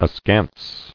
[a·skance]